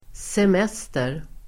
Uttal: [sem'es:ter]